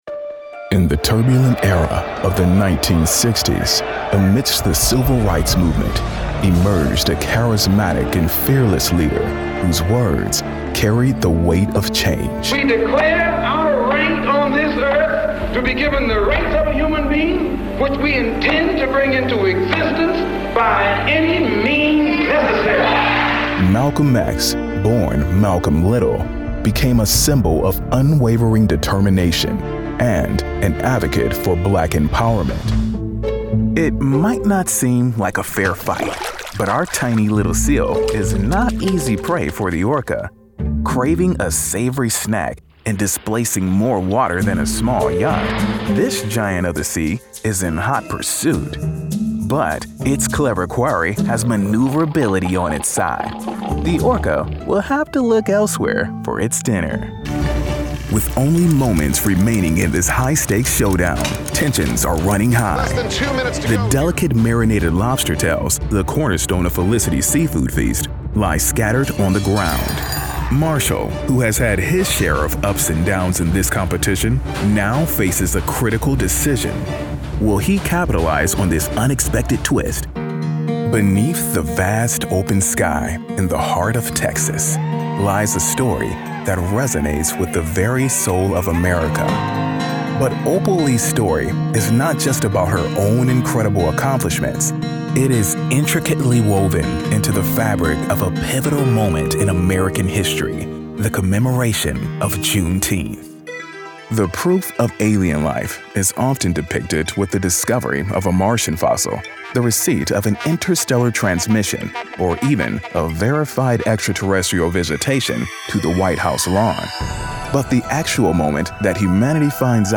TV/Doc Narration - Informative, Relatable, Friendly, Sincere
From his home studio he narrates voiceovers with an articulate, genuine, conversational, and authentic feel.